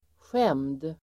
Uttal: [sjem:d]